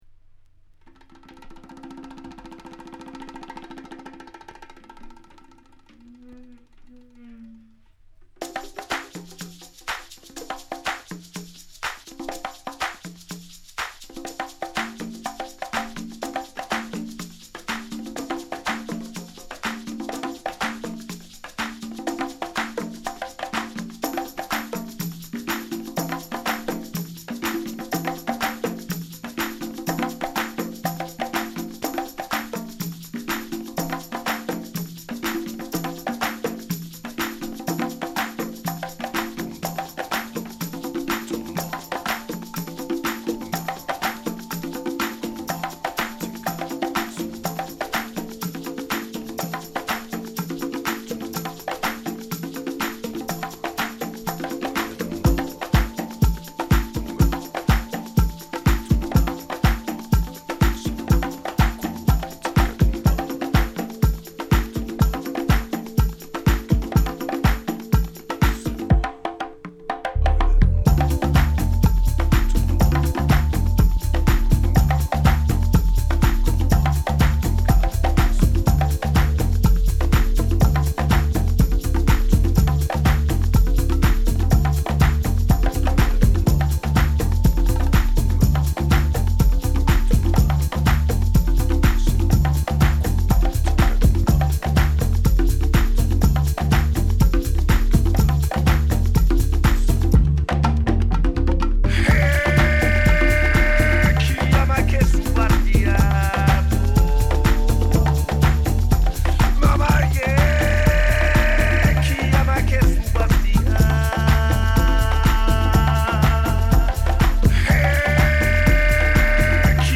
Deep House , Dub House